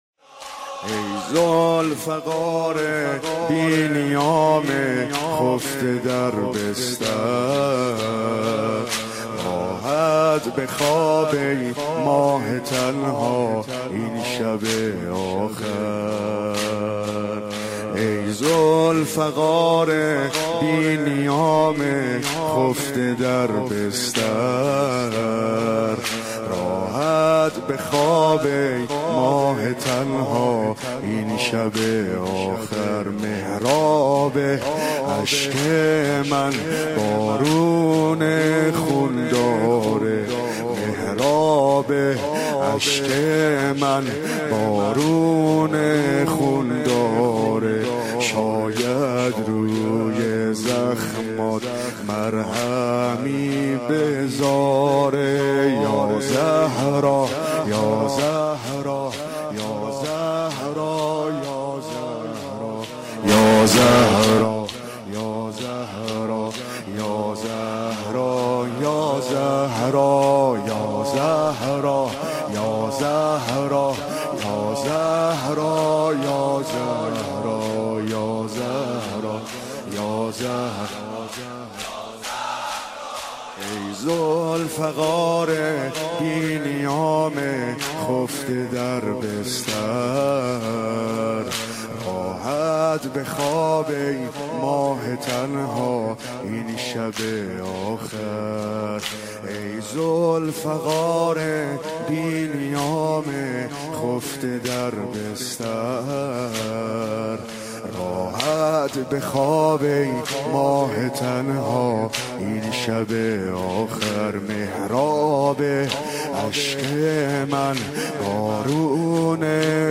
مداحی و نوحه
سینه زنی، شهادت حضرت زهرا(س